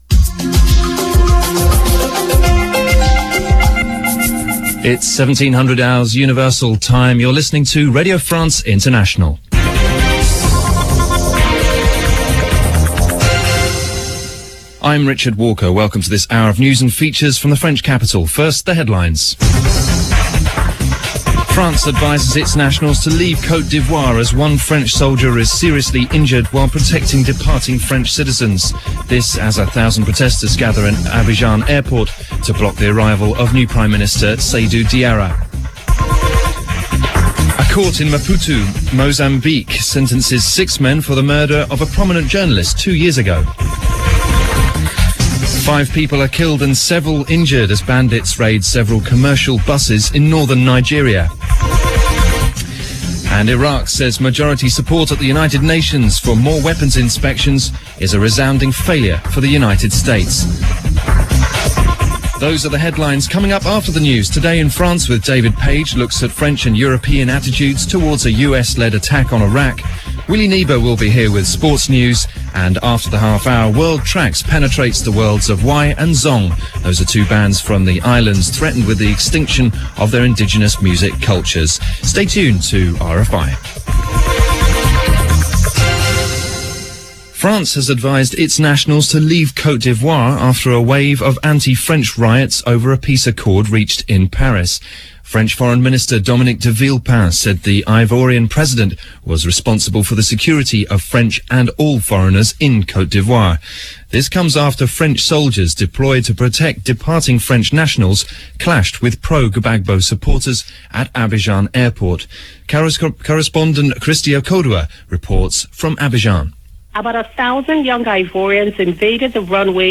Radio France International – English Service – News And Features – January 31, 2003 – RFI, Paris –